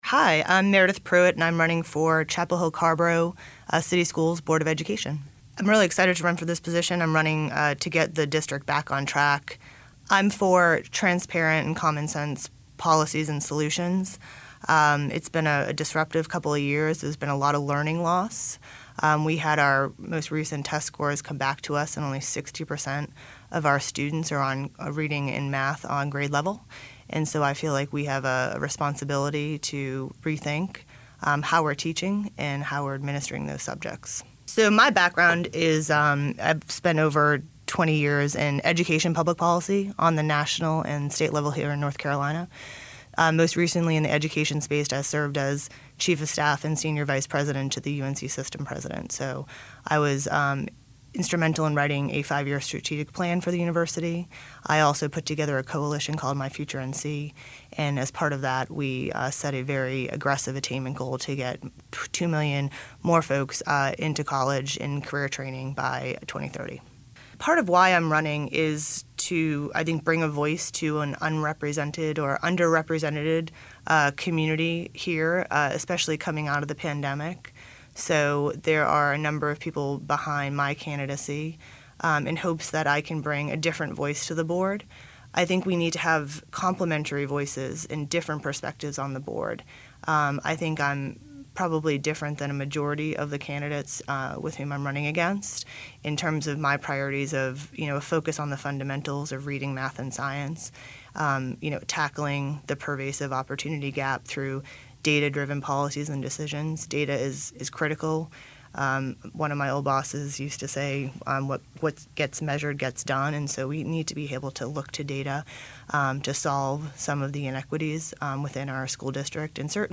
During this local election season, 97.9 The Hill and Chapelboro spoke with candidates for each of the races in Chapel Hill, Carrboro and Hillsborough. Each answered the same set of questions regarding their decision to run for elected office, their background in the community and what they wish for residents to think of when voting this fall.